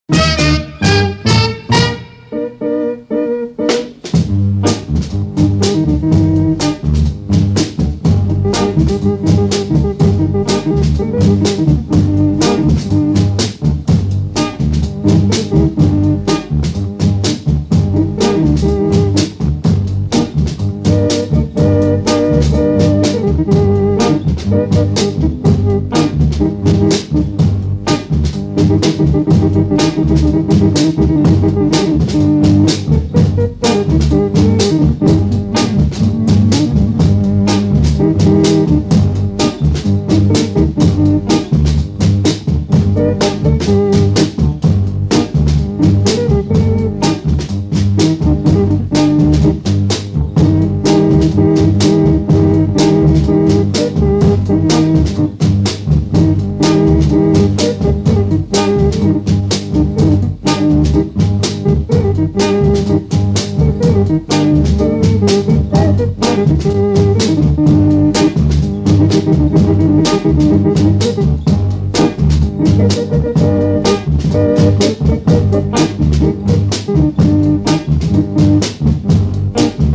ethio-jazz